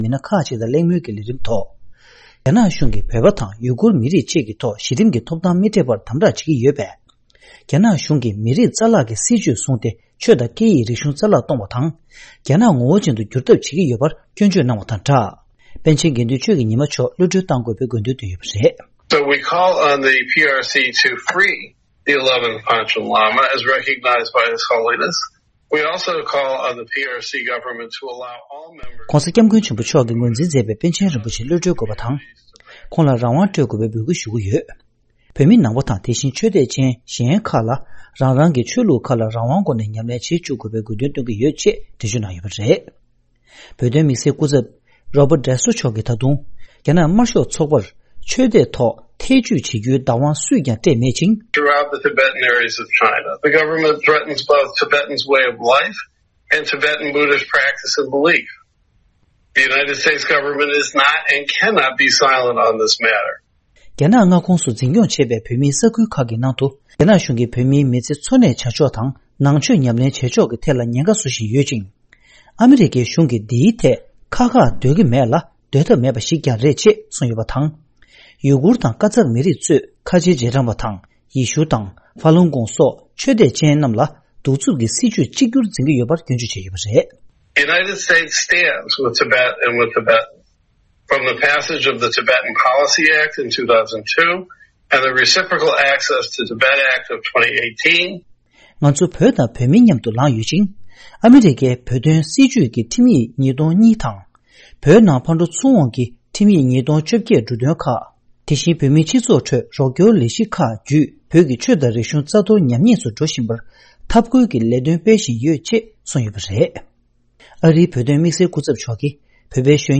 རྒྱལ་སྤྱིའི་བོད་དོན་ལས་འགུལ་ཁང་གིས་གོ་སྒྲིག་ཞུས་པའི་གླེང་མོལ་སྐབས། ༡༡།༡༩།༢༠ རྒྱལ་སྤྱིའི་བོད་དོན་ལས་འགུལ་ཁང་གིས་གོ་སྒྲིག་ཞུས་པའི་གླེང་མོལ་སྐབས། ༡༡།༡༩།༢༠
སྒྲ་ལྡན་གསར་འགྱུར།